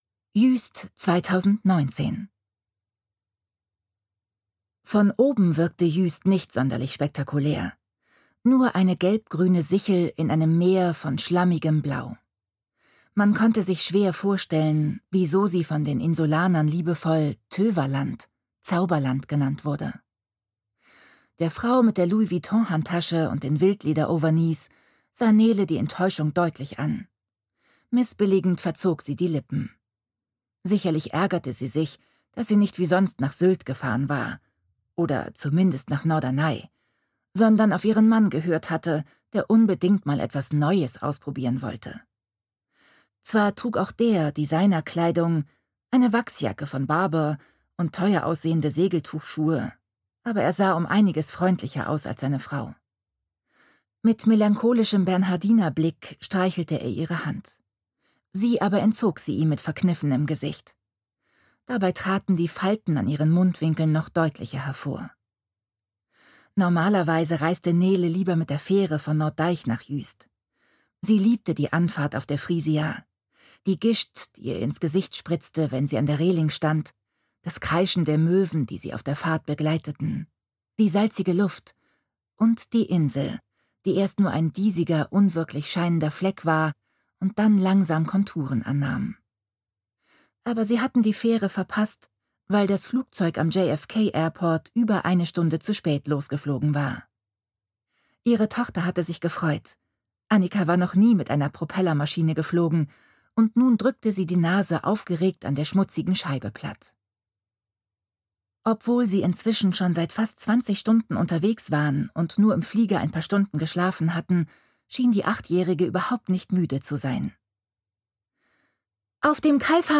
Autorisierte Lesefassung